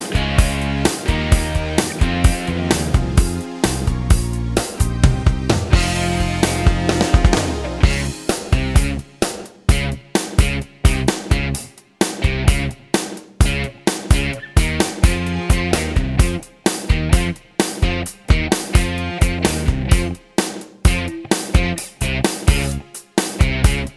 No Backing Vocals Duets 4:59 Buy £1.50